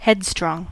Ääntäminen
Synonyymit obsessive willful set in one's ways Ääntäminen US Haettu sana löytyi näillä lähdekielillä: englanti Käännöksiä ei löytynyt valitulle kohdekielelle.